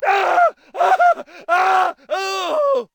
scream10.ogg